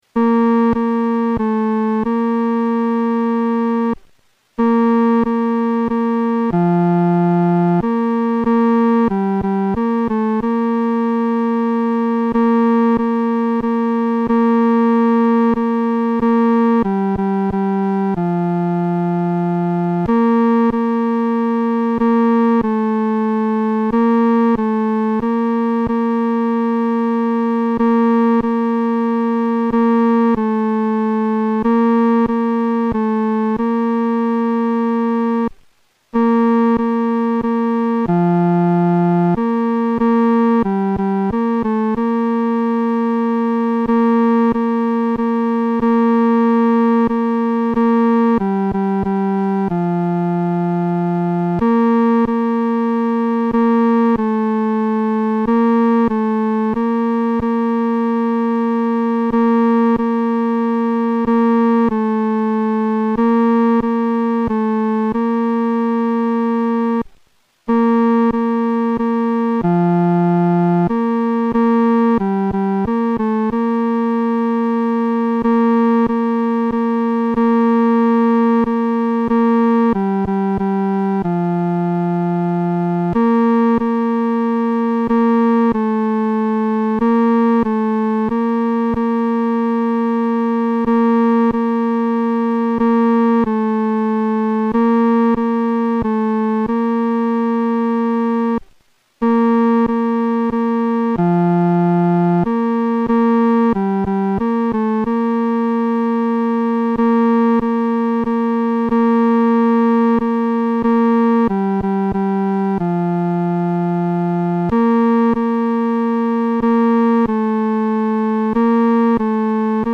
合唱
本首圣诗由网上圣诗班录制